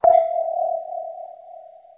ping.wav